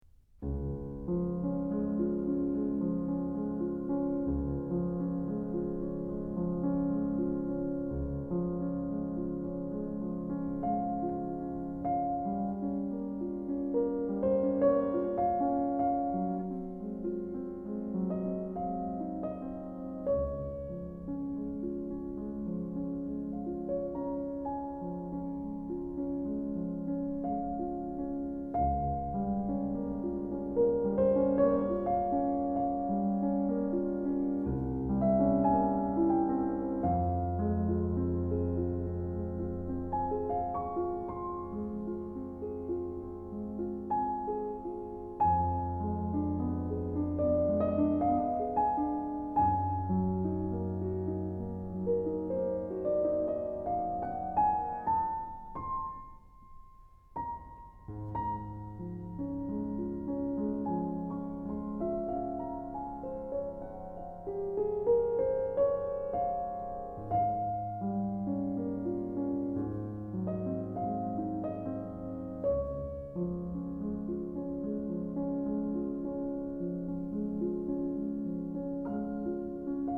☆ 精選12位古典作曲大師，19首曲調和緩柔美，旋律甜美動人的鋼琴獨奏作品，舒緩身心靈的最佳音樂。